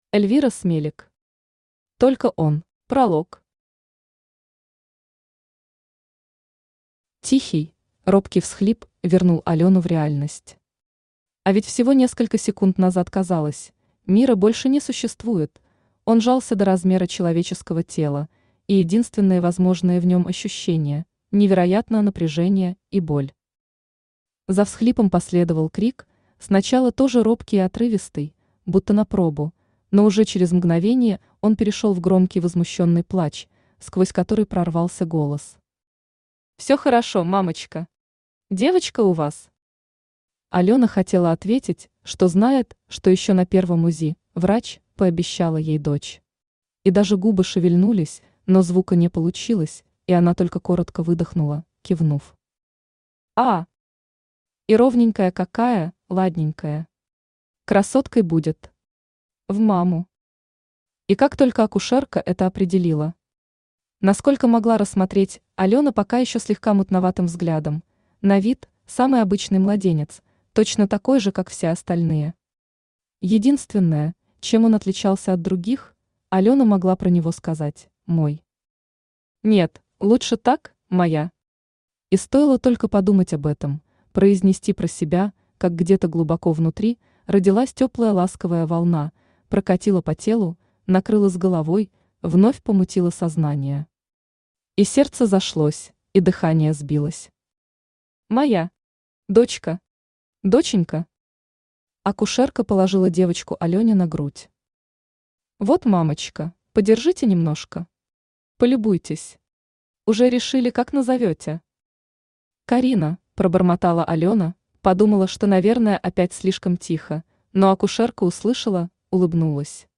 Aудиокнига Только он Автор Эльвира Смелик Читает аудиокнигу Авточтец ЛитРес.